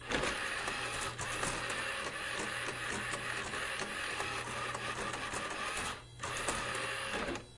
描述：我的CD驱动器在打开时